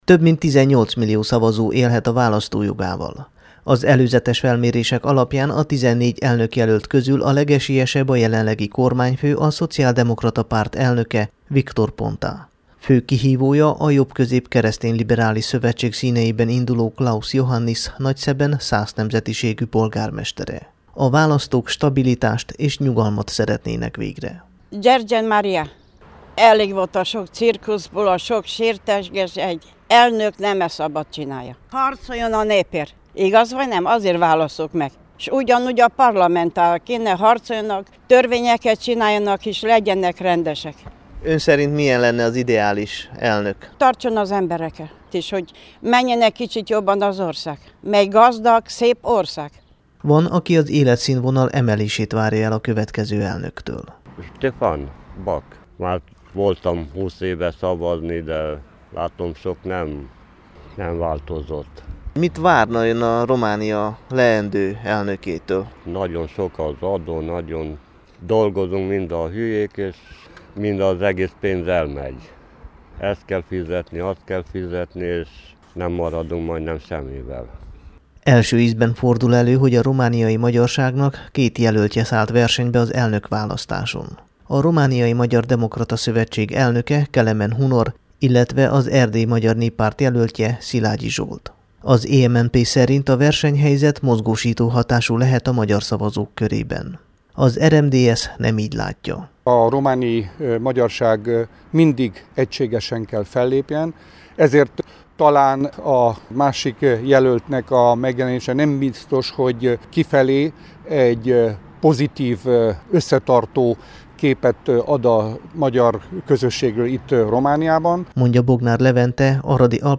Az Aradi Híreknek nyilatkozó aradi szavazópolgárok a jobb megélhetés reményében járultak az urnák elé a vasárnapi elnökválasztáson.
rádióriportja vasárnap délelőtt a 93-as számú, a Csiky Gergely Főgimnáziumban berendezett szavazóhelyiségben készült.